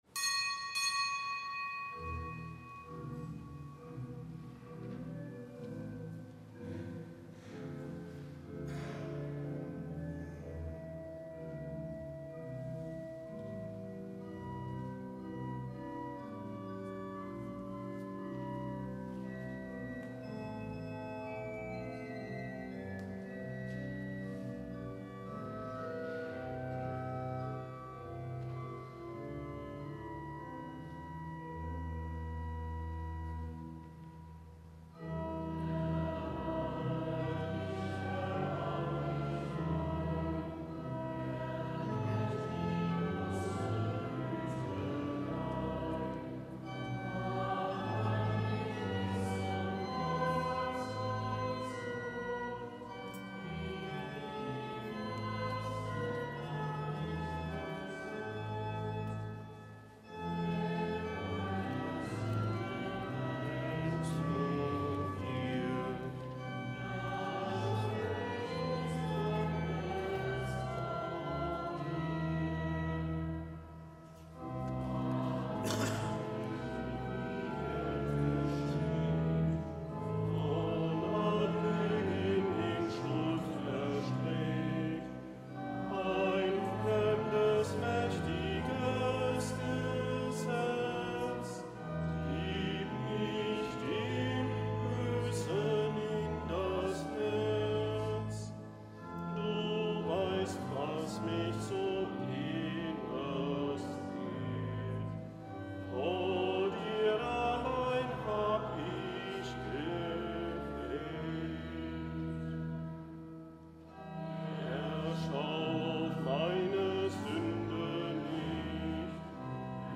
Kapitelsmesse aus dem Kölner Dom am Freitag der zweiten Fastenwoche. Zelebrant: Weihbischof Rolf Steinhäuser.